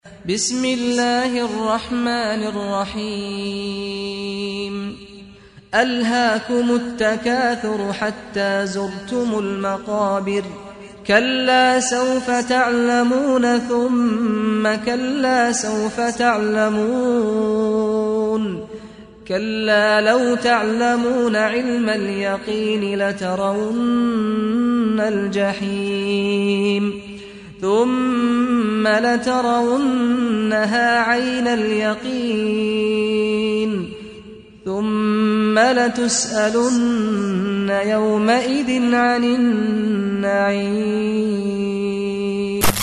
Islam-media, Saad Al-Ghamidi : récitateur du coran
Le saint Coran par Saad Al-Ghamidi